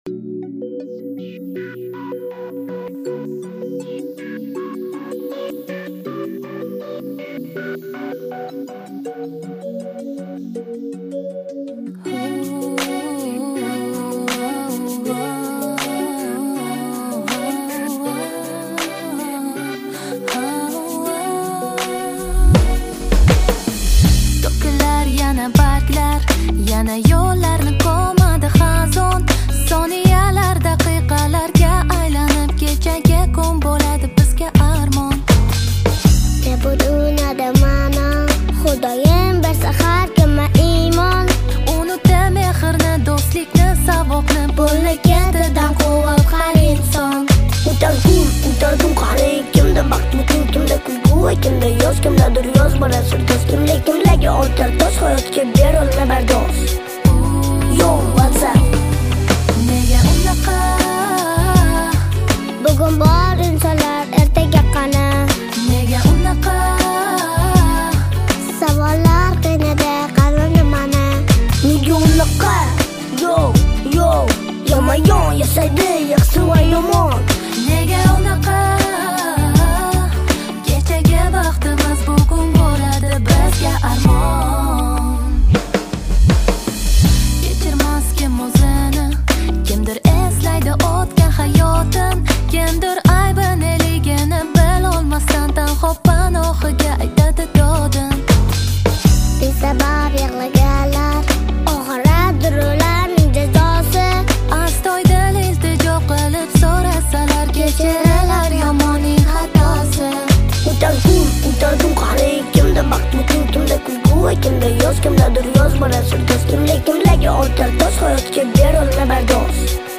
[17/7/2010]女声、童声 Si Ya No Te Vuelvo A Ver 激动社区，陪你一起慢慢变老！